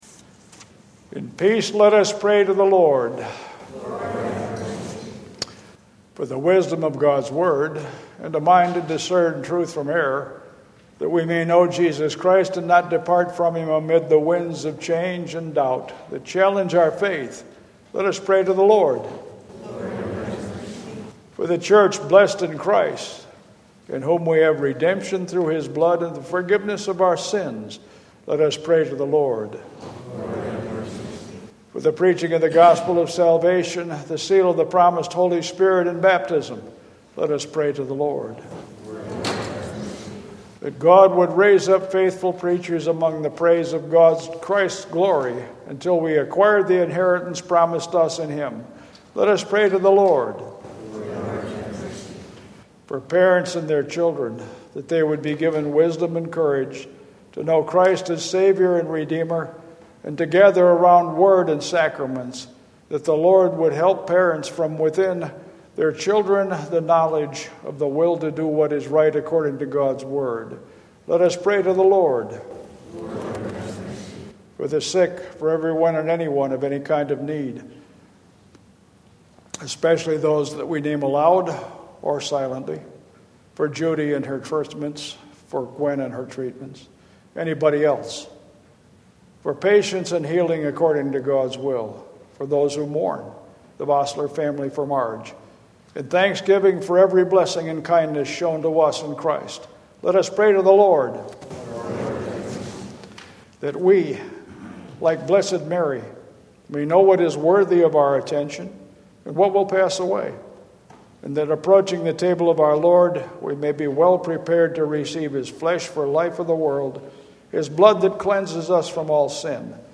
17 Prayer of the Church.mp3